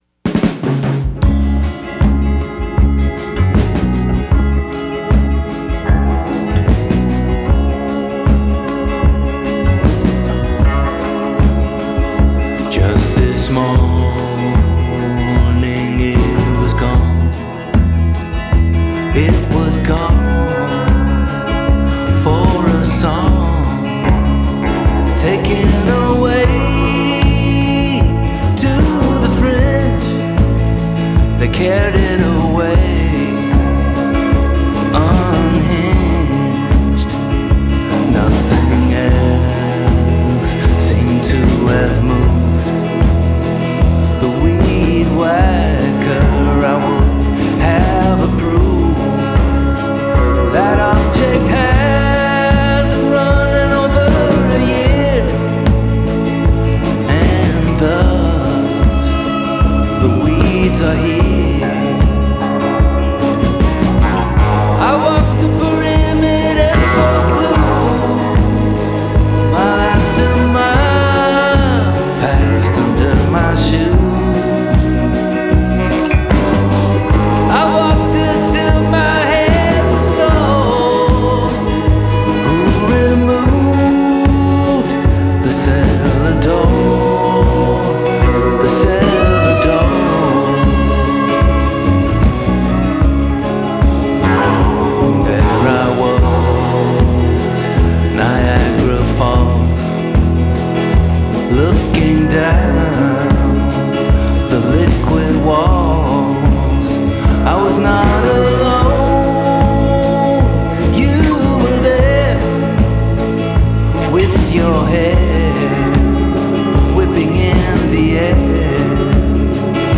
There is mystery here without gimmicks.